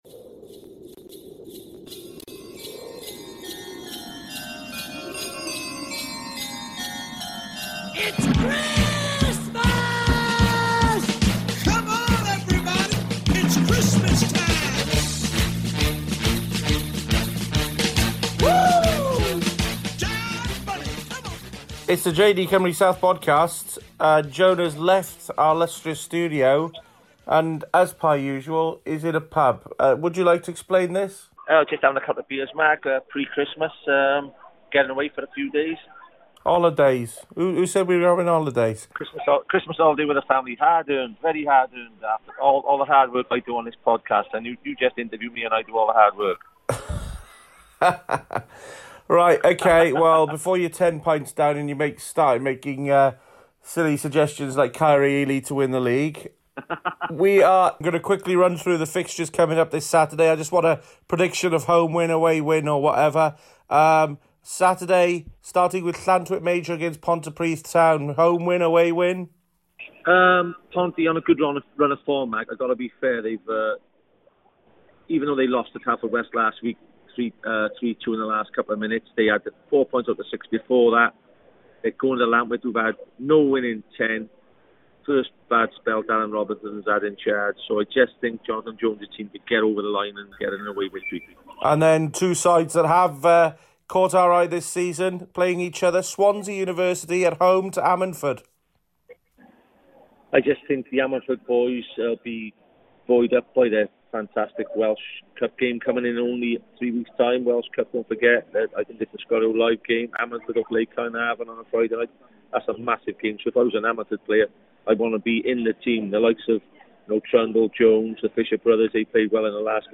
so the Cymru South weekend preview comes to you from Wetherspoons.